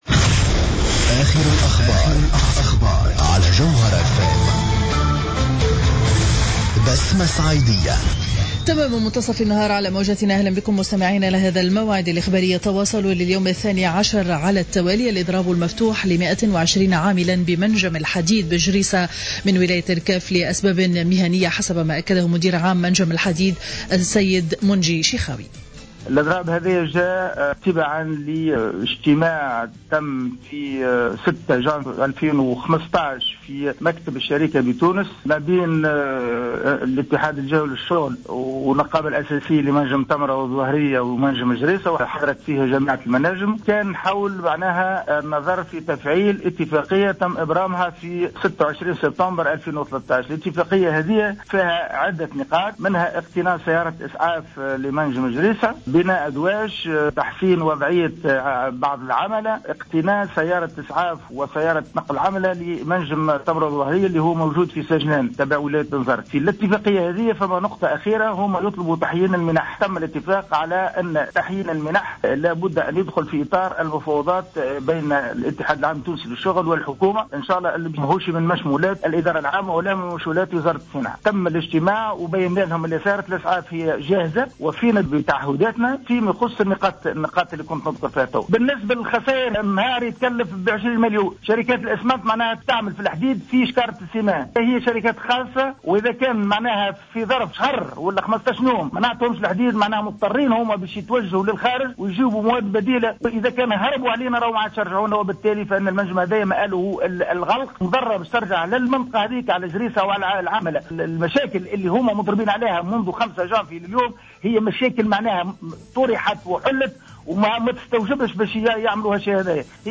نشرة أخبار منتصف النهار ليوم الأحد 18-01-15